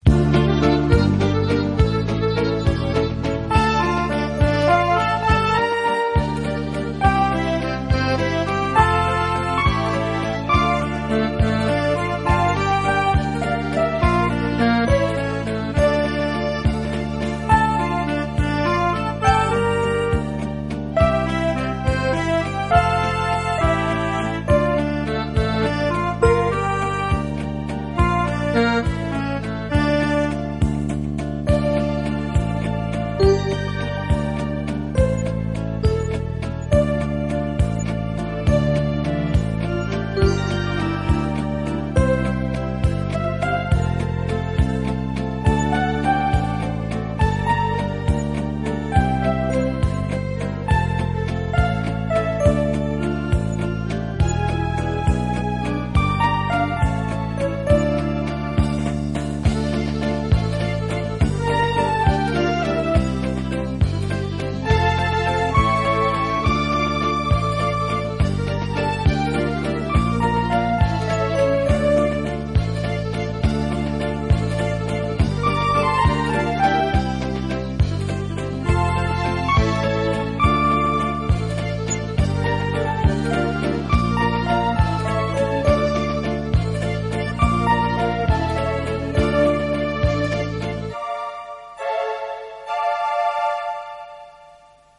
ГІМН
Гімн школи(мінусовка)